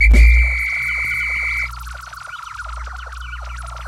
Olimar's whistle in Pikmin 2. This file was ripped directly from the game's ISO.
P2_whistle_Olimar.ogg.mp3